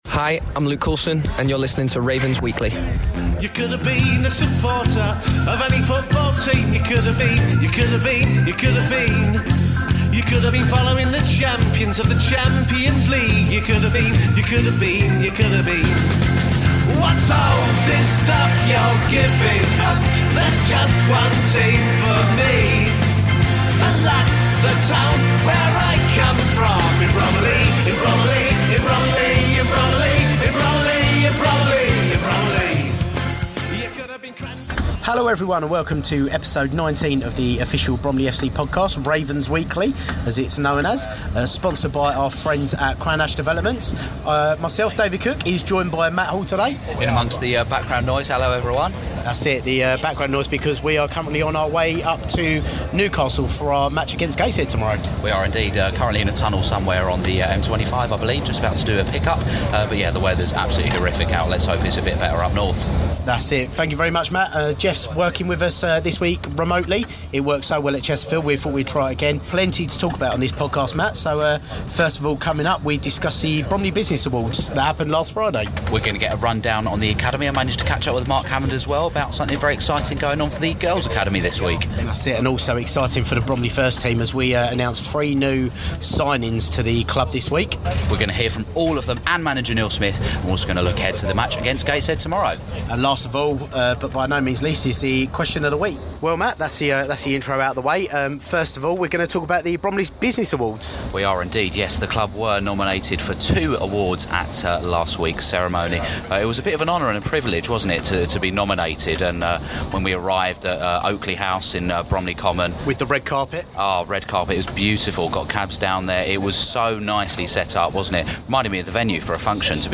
This week’s episode includes an audio diary of the trip up to Gateshead, details of the Club's victories at the Bromley Business Awards, all the need to know details on the latest squad update, interviews with new signings